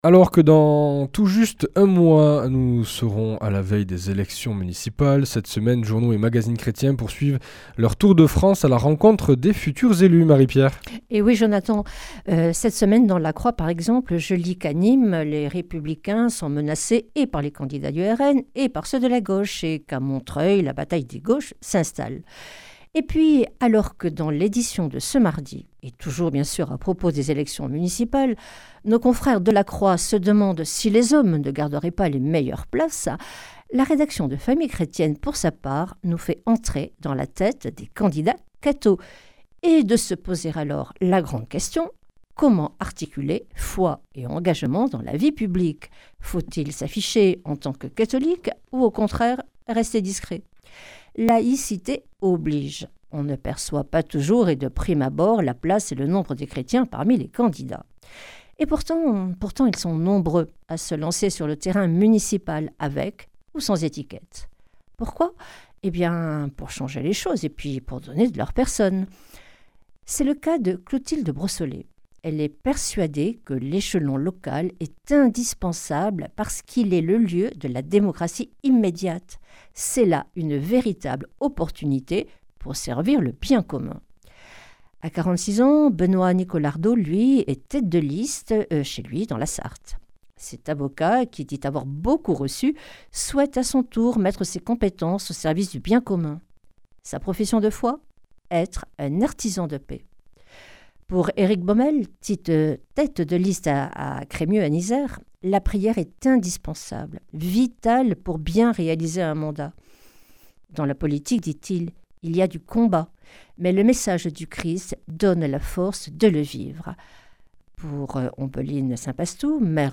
Journaliste